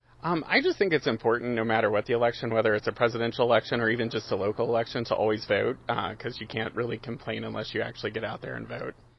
Hear from a voter